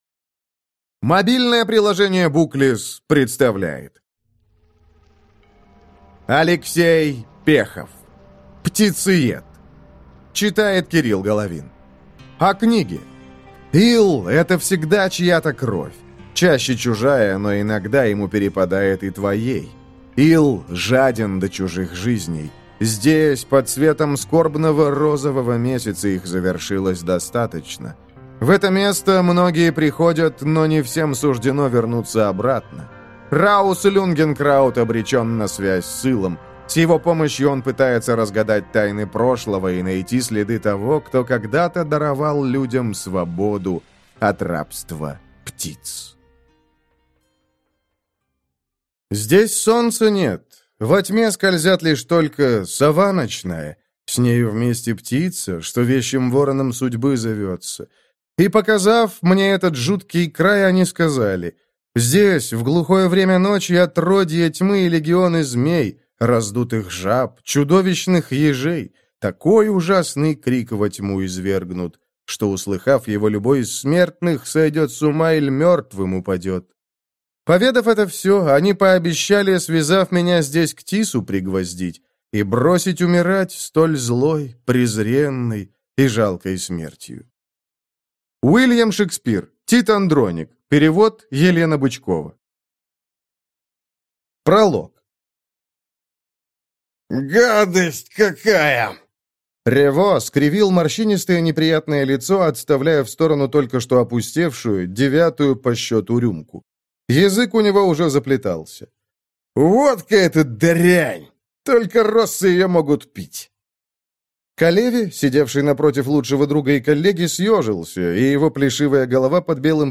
Слушать аудиокнигу Танец с демоном.